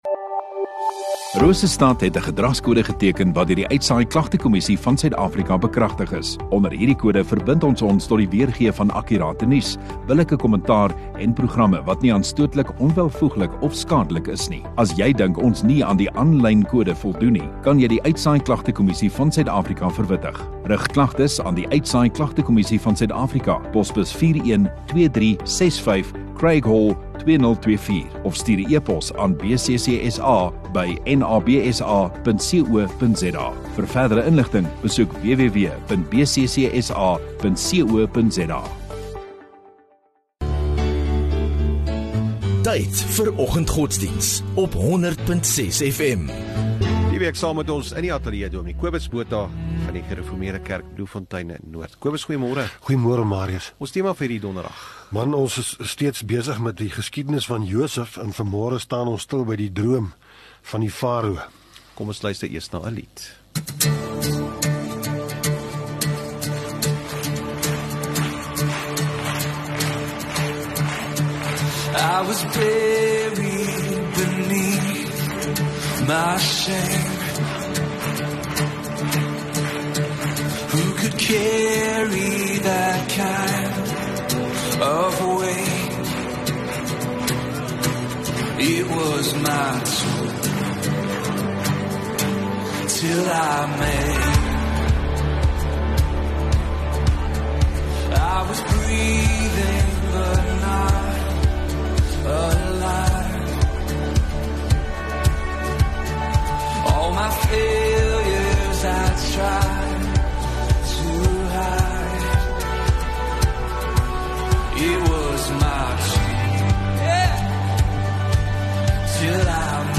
12 Sep Donderdag Oggenddiens